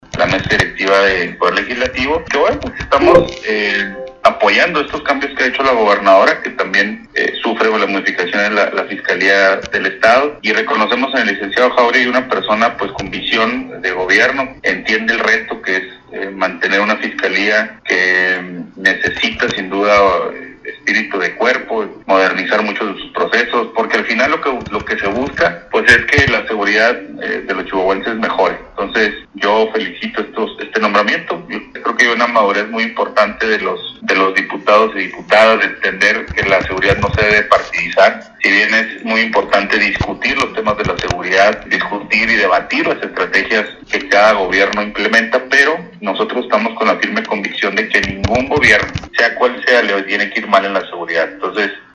El diputado Alfredo Chávez, dijo confiar en la gestión que realizará el nuevo fiscal de Chihuahua César Jáuregui, quien ayer rindió protesta, tras ser propuesto por la gobernadora.